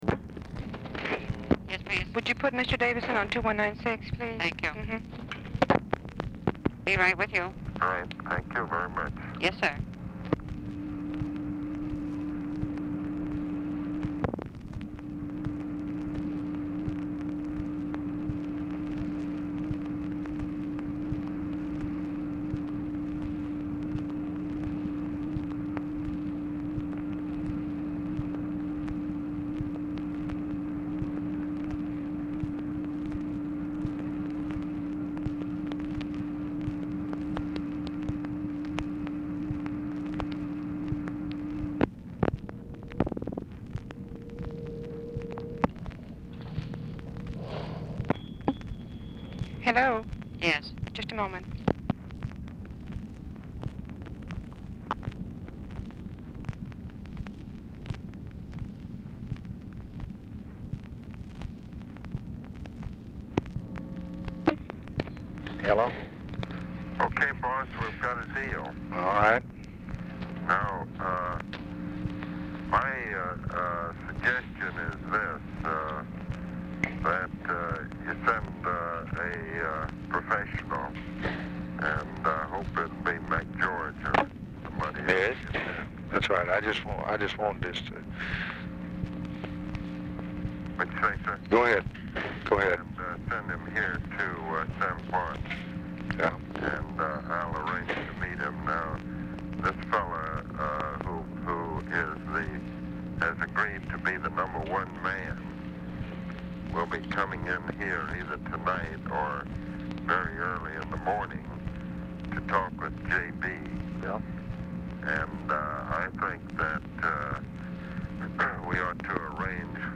Format Dictation belt
Location Of Speaker 1 Oval Office or unknown location
Specific Item Type Telephone conversation Subject Communist Countries Defense Diplomacy Latin America Public Relations